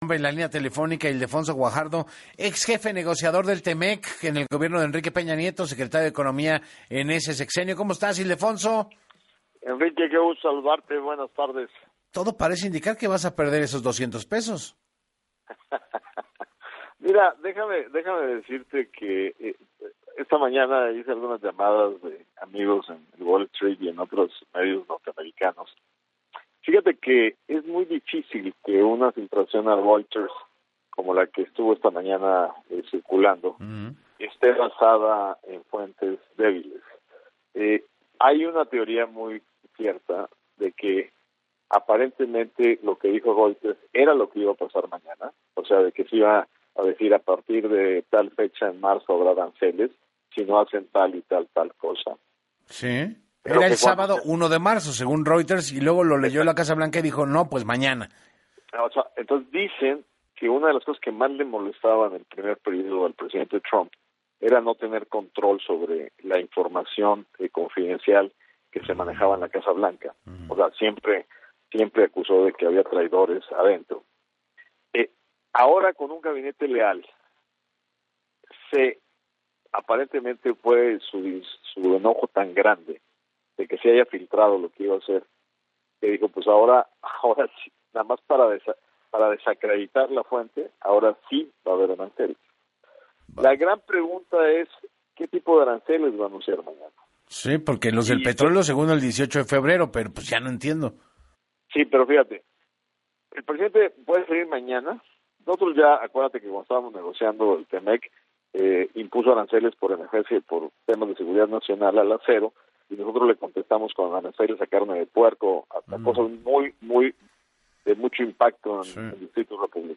Ante el anuncio de Donald Trump de que impondrá aranceles del 25 por ciento a México y Canadá, así como el 10 por ciento a China a partir del sábado 1 de febrero, el exsecretario de Economía en el Gobierno de Enrique Peña Nieto, Ildefonso Guajardo, dijo que México tiene que contrarrestar las consecuencias que serán fuertes para la economía del país y responder con aranceles que sean importantes para Estados Unidos basados en el Tratado entre México, Estados Unidos y Canadá (T-MEC), así lo informó en entrevista con Enrique Hernández Alcázar.